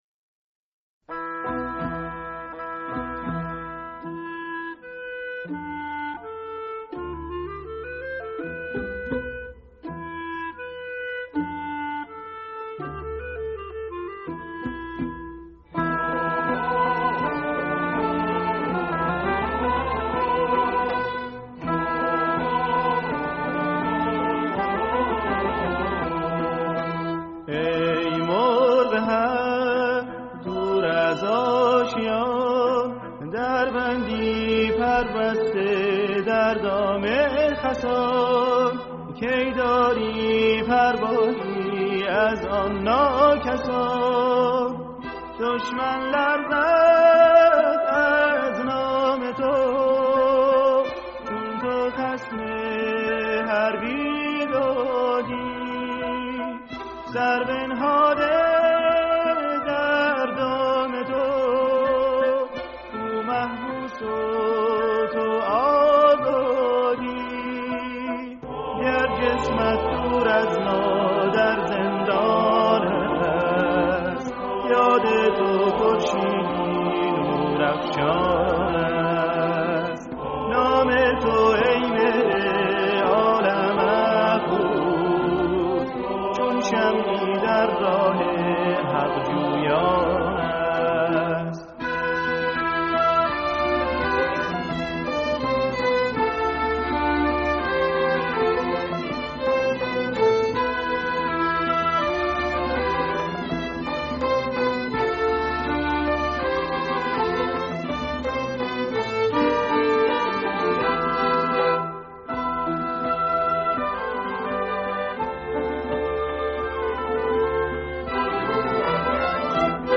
تصنیف در وصف آزادگان دفاع مقدس ، با همراهی گروه كر